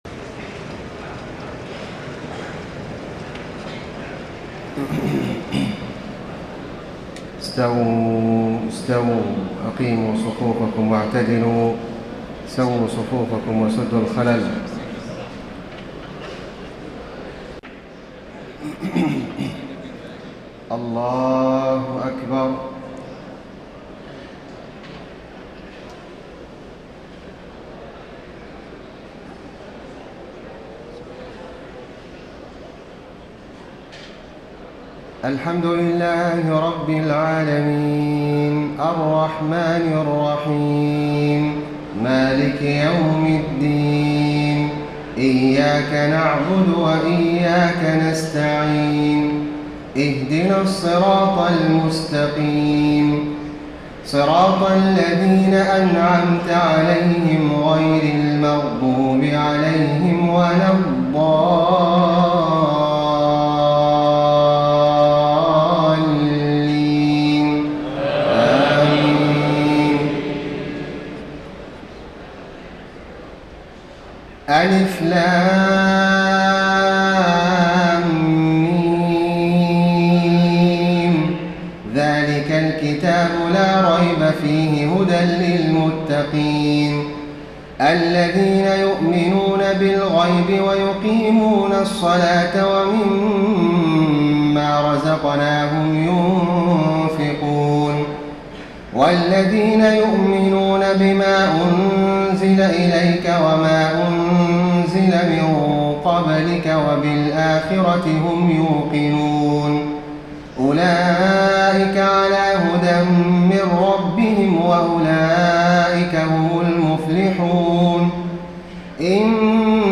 تهجد ليلة 21 رمضان 1433هـ من سورة البقرة (1-91) Tahajjud 21 st night Ramadan 1433H from Surah Al-Baqara > تراويح الحرم النبوي عام 1433 🕌 > التراويح - تلاوات الحرمين